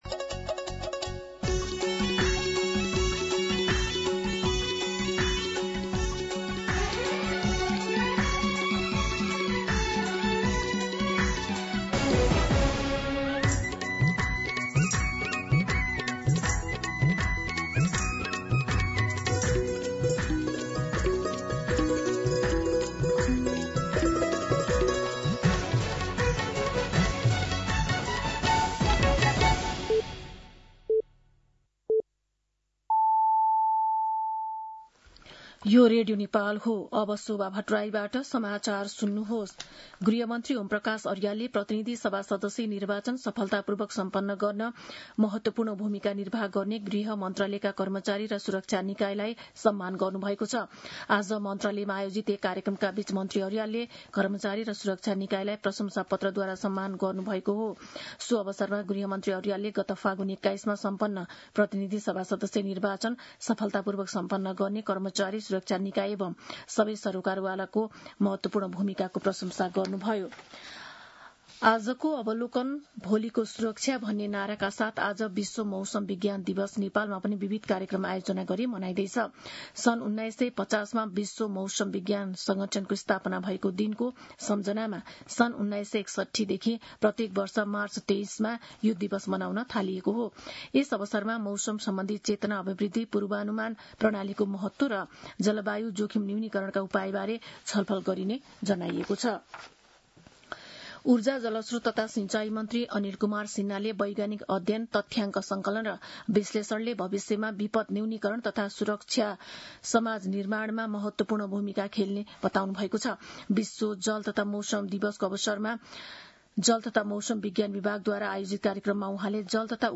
दिउँसो ४ बजेको नेपाली समाचार : ९ चैत , २०८२